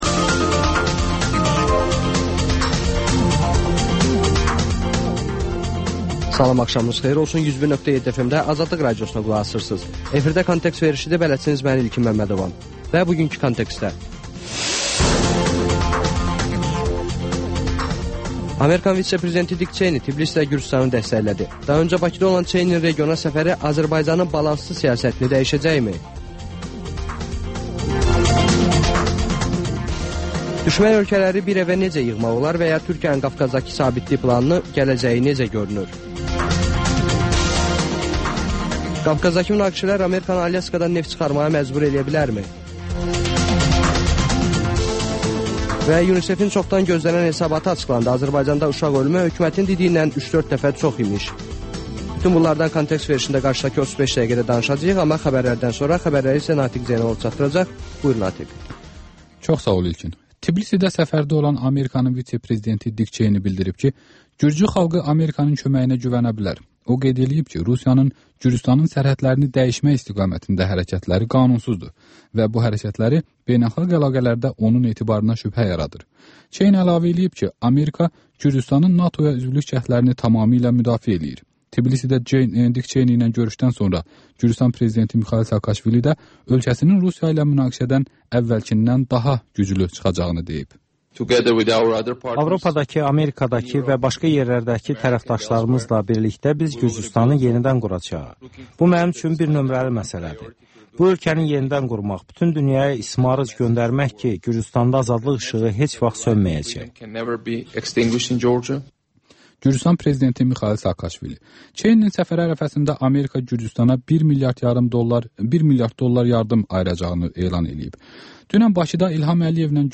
Xəbərlər, müsahibələr, hadisələrin müzakirəsi, təhlillər, sonda TANINMIŞLAR: Ölkənin tanınmış simaları ilə söhbət